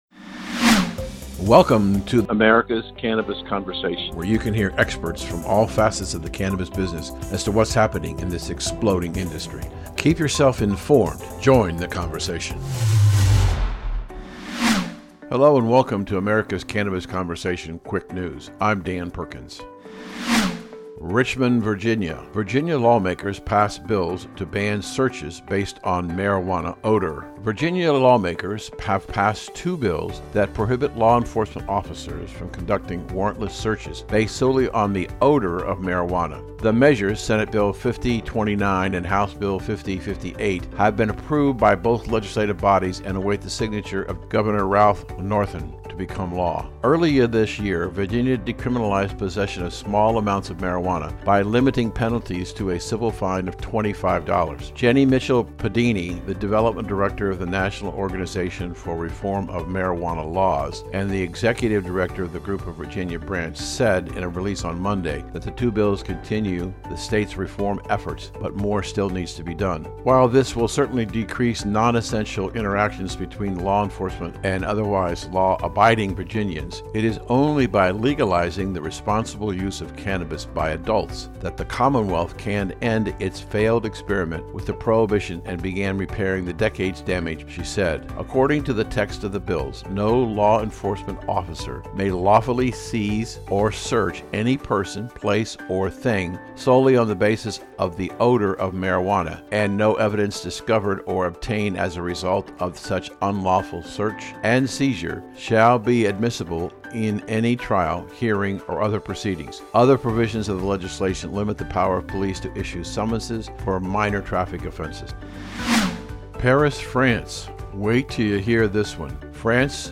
Please consider subscribing to this talk show.
Our program brings on-air: industry leaders, elected officials, lawmakers, medical professionals, local experts, industry experts, dispensary owners, growers, operators, investors, and more.